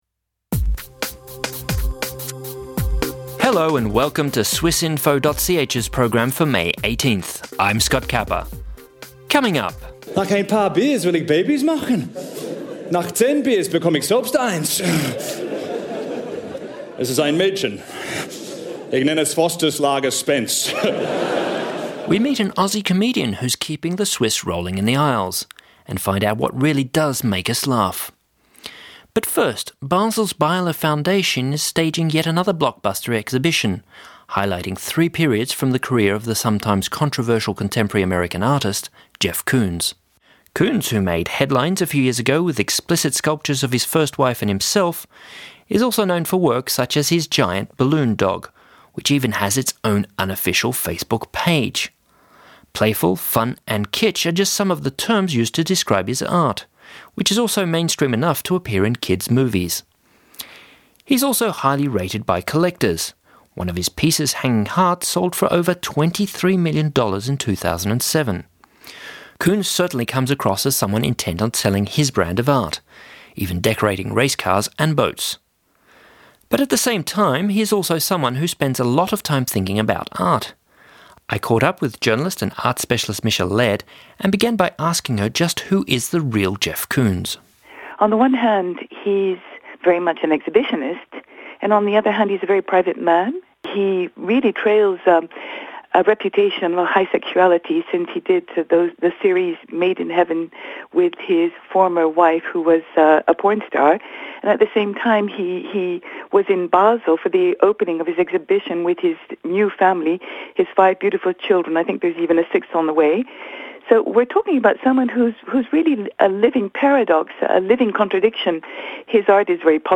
Mainstream contemporary art bursts on the scene at the Beyeler, laughter on the brain and we talk to an Australian comedian going for guffaws in Switzerland.